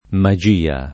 magia [ ma J& a ] s. f.